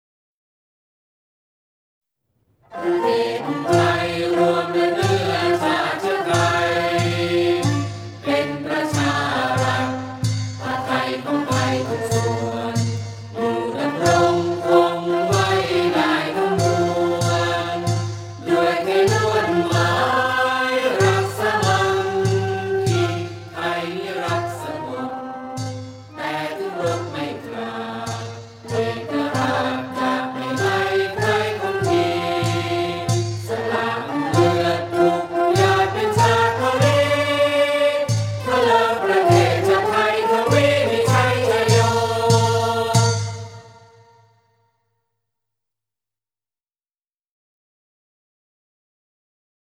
เพลงชาติ (วงมโหรี)